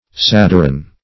Search Result for " sadiron" : The Collaborative International Dictionary of English v.0.48: Sadiron \Sad"i`ron\, n. [Probably sad heavy + iron.] An iron for smoothing clothes; a flatiron.